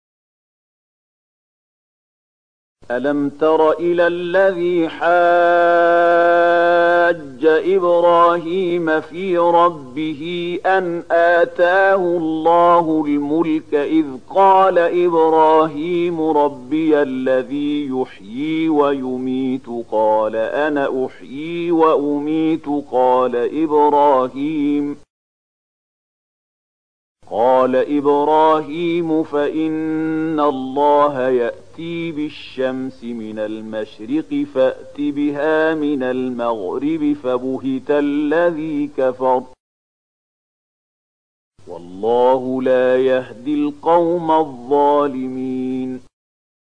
002258 Surat Al-Baqarah ayat 258 dengan bacaan murattal Syaikh Mahmud Khalilil Hushariy: